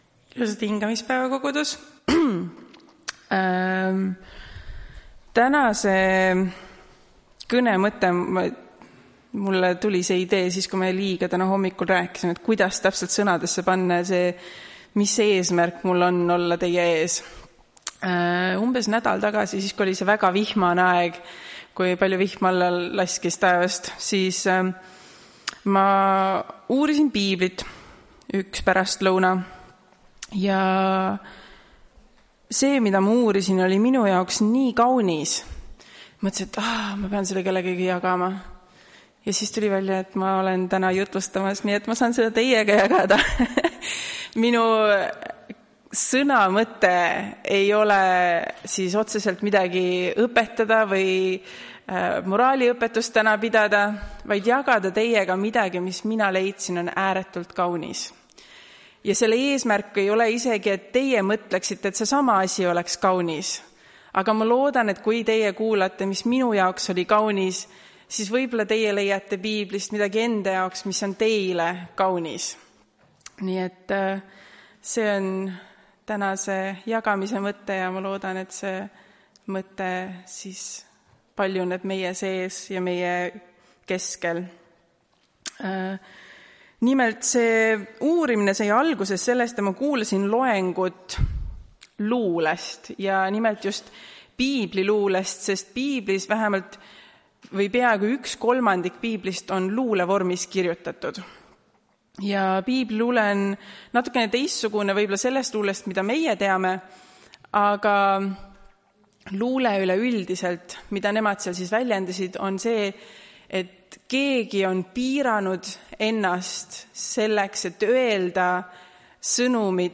Tartu adventkoguduse 31.05.2025 hommikuse teenistuse jutluse helisalvestis.